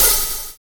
Closed Hats
Wu-RZA-Hat 44.wav